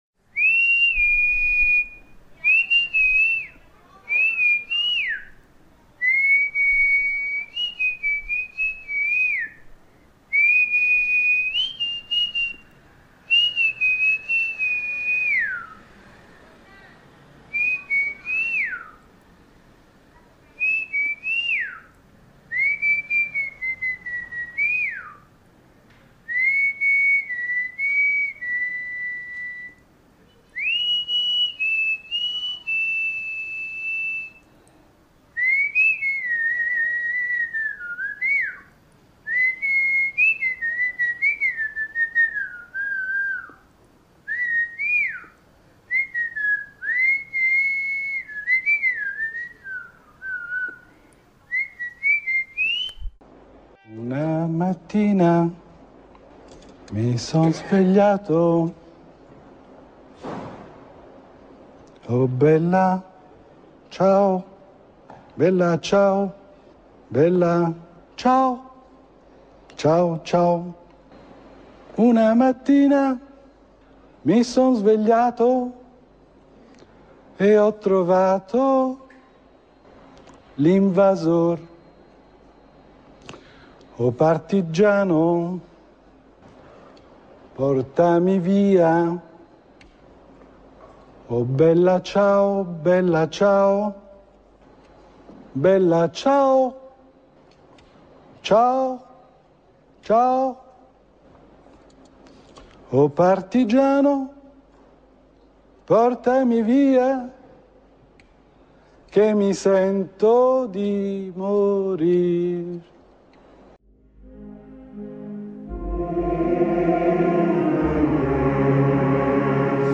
In concomitanza con il progetto INSIEME – PERCORSI DI RICERCA TRA ARTE E TERRITORIO, a cura di Zerynthia, nella CASA DI RECLUSIONE DI PALIANO verrà installata per i detenuti una selezione di lavori sonori provenienti dall’archivio di RAM radioartemobile.
Un pettirosso parla  degli effetti devastanti del cosiddetto capitalismo vincente.
Un discorso incisivo di poche parole.
La famosa aria del  Nabucco di Giuseppe Verdi viene dall’artista fortemente rallentata per renderla più struggente e farne un rituale di memoria collettiva.
L’artista dedica questa canzone melanconica agli uccelli che vengono a fargli visita.
[Materiali sonori dagli archivi del SoundArtMuseum di RAM radioartemobile]